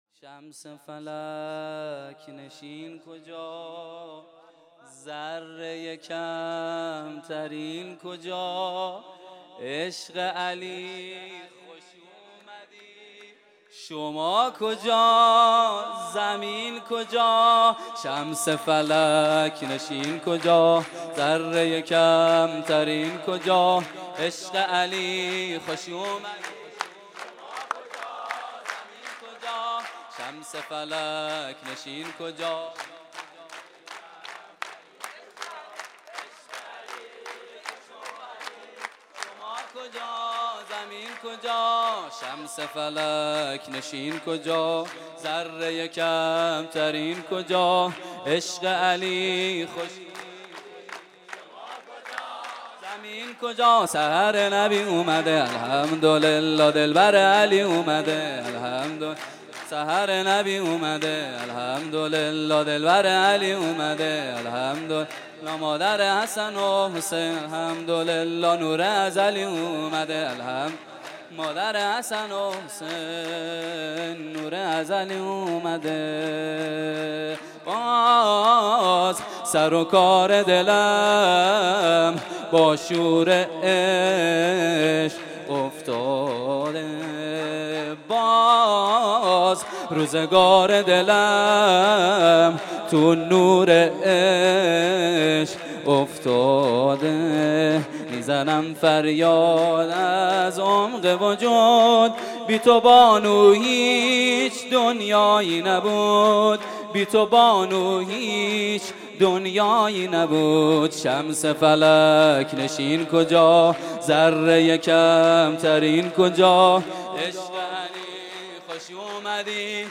خیمه گاه - هیئت بچه های فاطمه (س) - سرود | شمس فلک نشین
مراسم جشن ولادت حضرت فاطمه الزهرا (س)